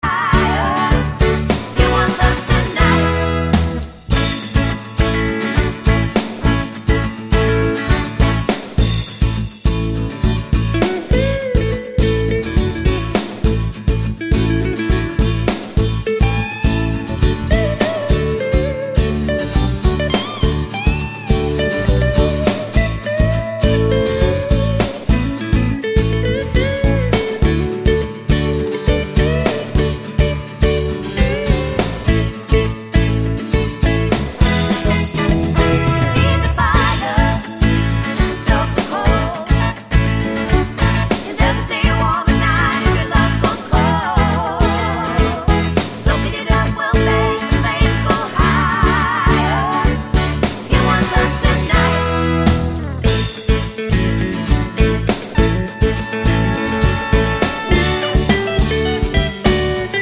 no Backing Vocals Crooners 2:38 Buy £1.50